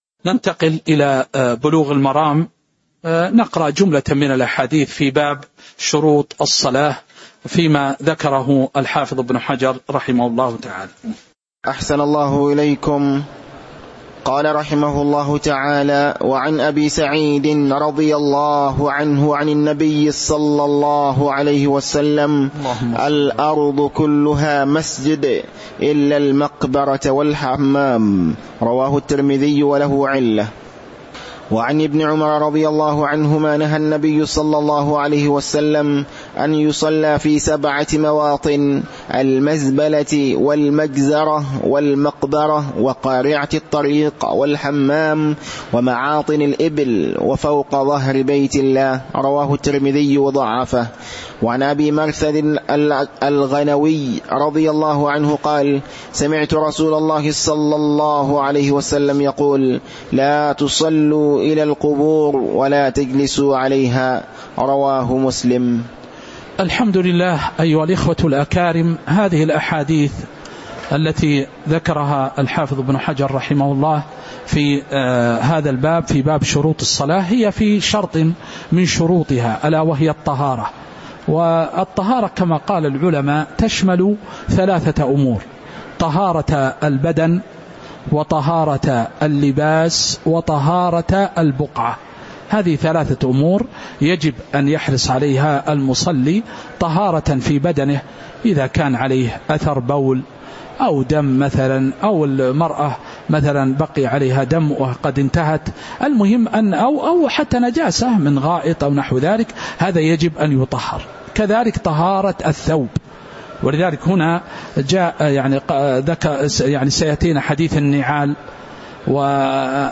تاريخ النشر ١٧ صفر ١٤٤٥ هـ المكان: المسجد النبوي الشيخ